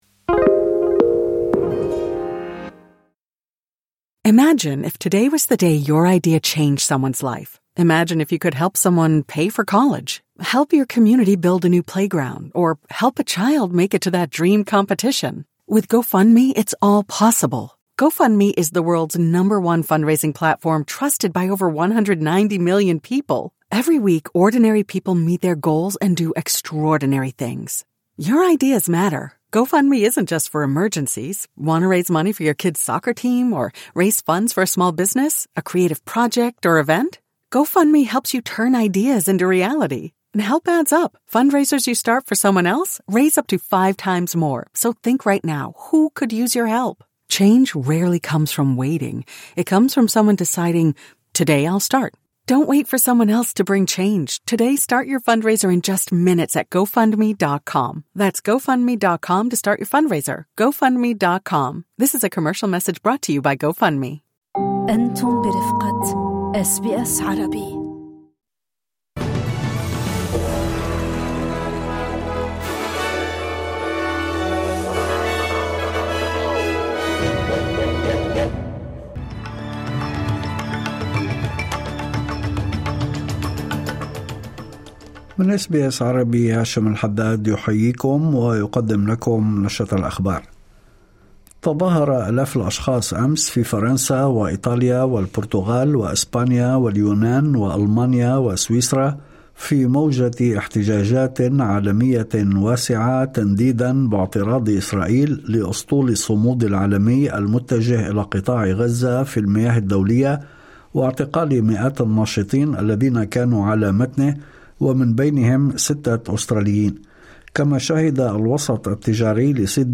نشرة أخبار الظهيرة 03/10/2025